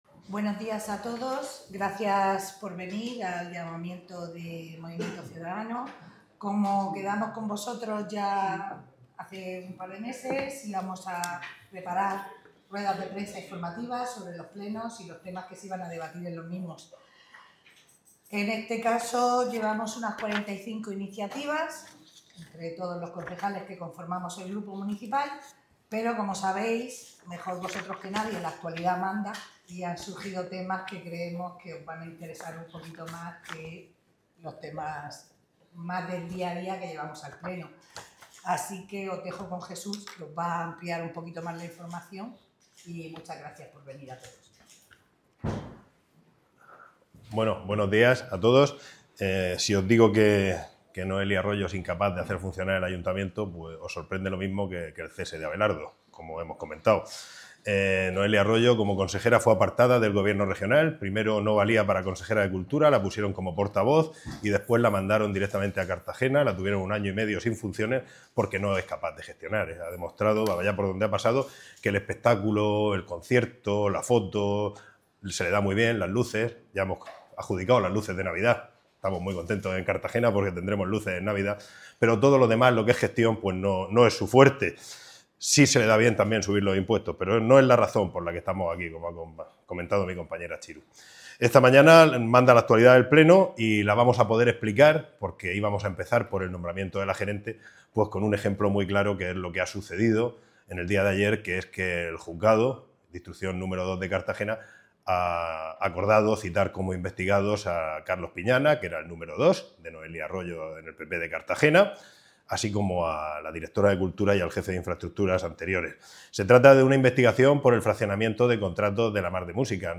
Audio: Rueda de prensa MC Cartagena (MP3 - 13,54 MB)
El portavoz de MC Cartagena, Jesús Giménez Gallo, así como la portavoz adjunta, Mercedes Graña, han comparecido esta mañana en rueda de prensa para tratar temas de reciente actualidad, como es la citación como investigado del que fuera número 2 de Arroyo, Carlos Piñana, por el presunto fraccionamiento de contratos de La Mar de Músicas, lo que refleja el modus operandi del PP en el Ayuntamiento de Cartagena.